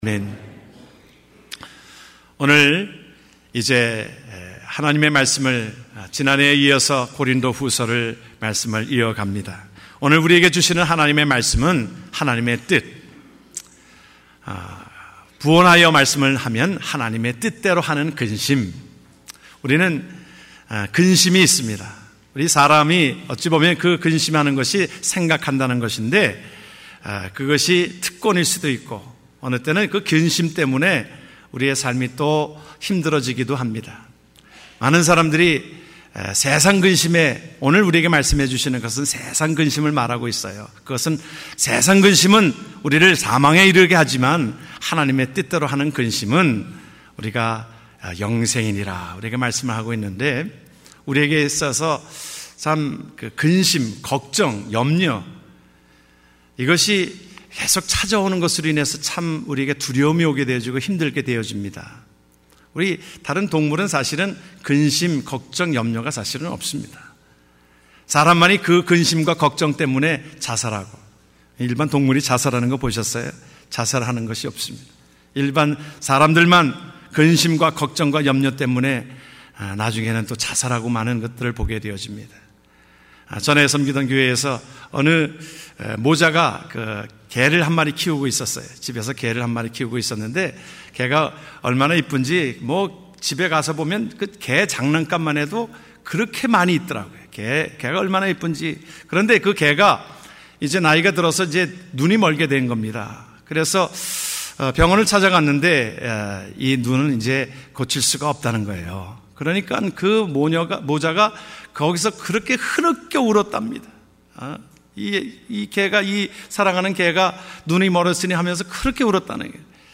2015년 1월 11일 미주평안교회 주일 설교말씀: 하나님의 뜻(고후 7:9-11)